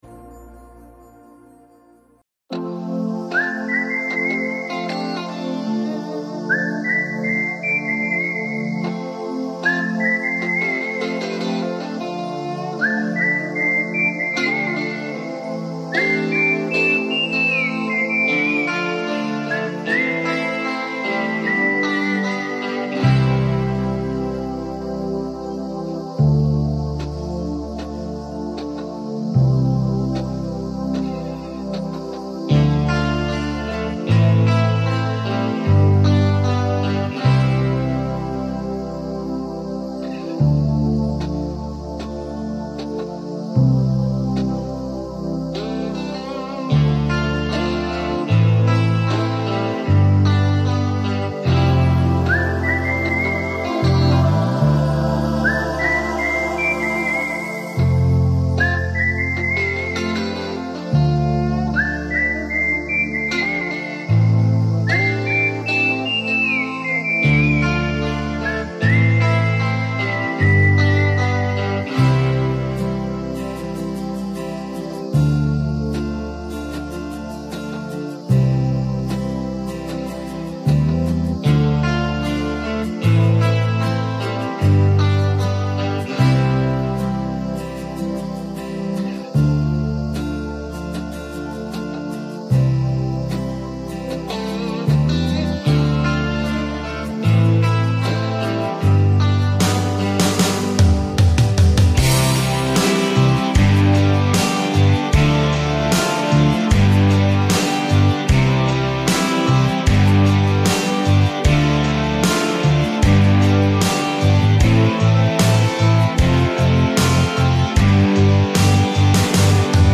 Karaoke Songs